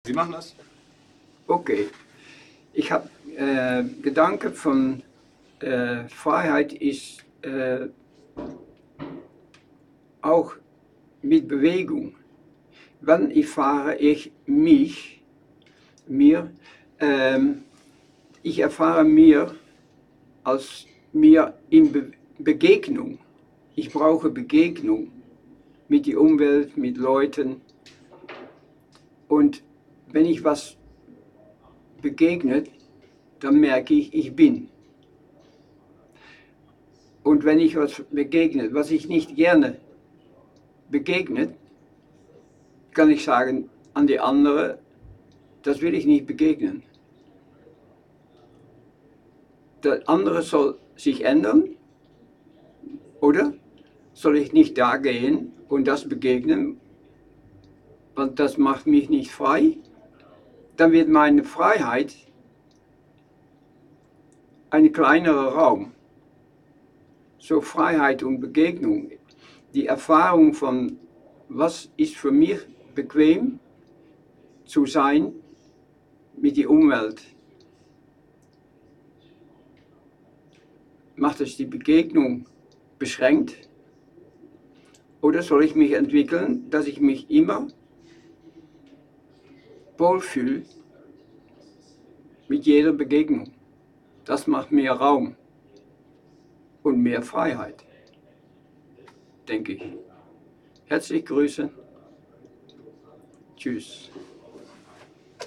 MS Wissenschaft @ Diverse Häfen
Standort war das Wechselnde Häfen in Deutschland. Der Anlass war MS Wissenschaft